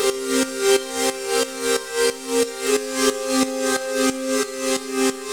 Index of /musicradar/sidechained-samples/90bpm
GnS_Pad-MiscA1:8_90-C.wav